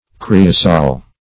Creosol \Cre"o*sol\ (kr[=e]"[-o]*s[=o]l), n. [Creosote +